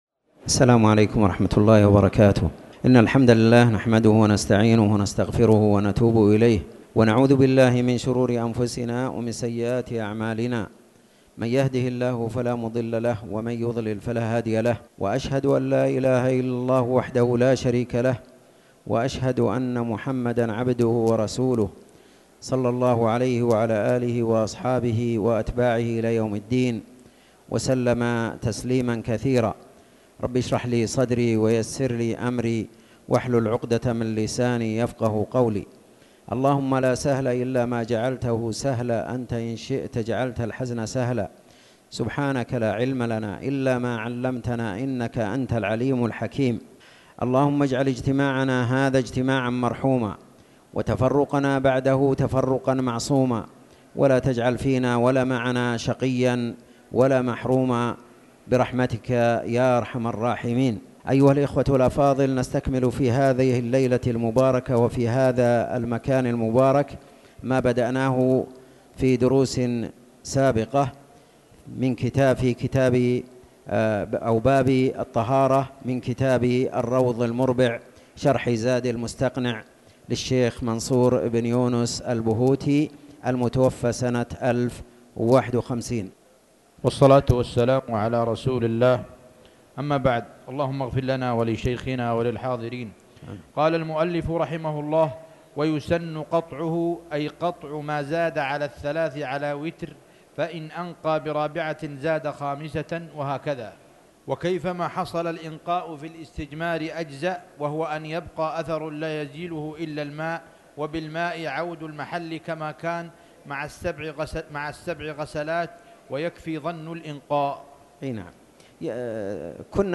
تاريخ النشر ١٠ جمادى الآخرة ١٤٣٩ هـ المكان: المسجد الحرام الشيخ